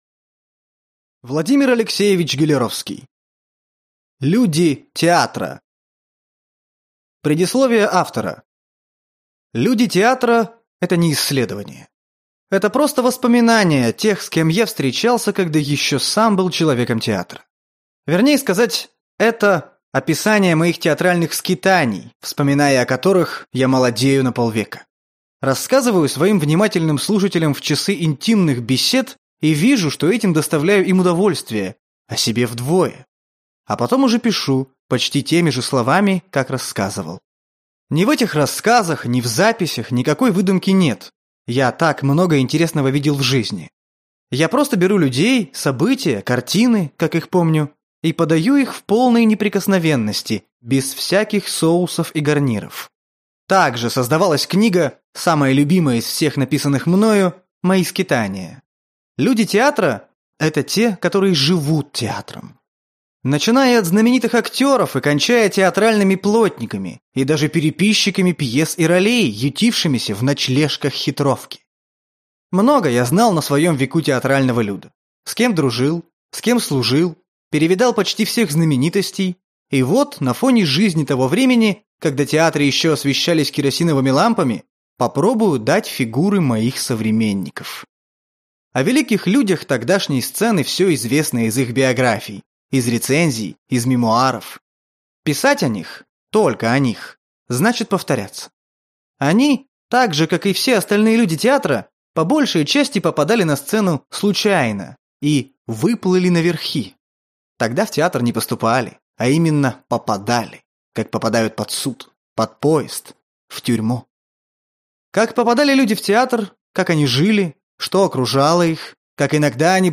Аудиокнига Люди театра | Библиотека аудиокниг